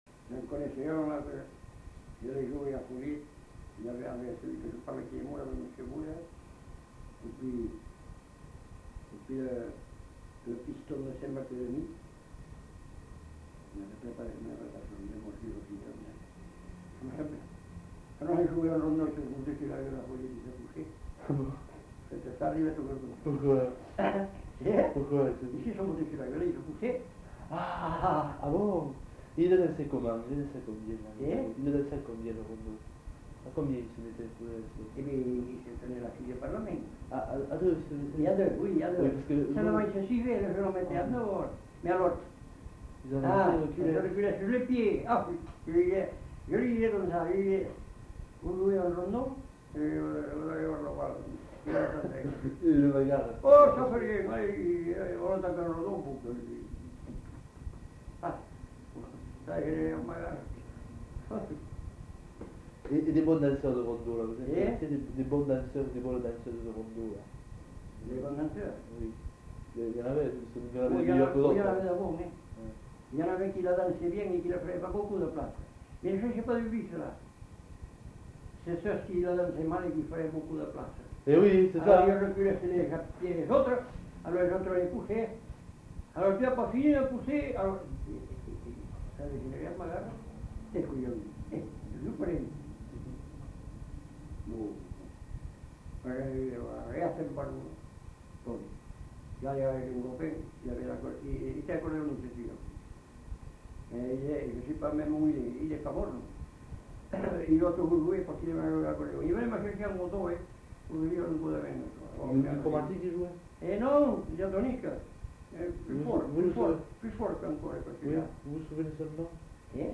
Lieu : Mas-d'Agenais (Le)
Genre : témoignage thématique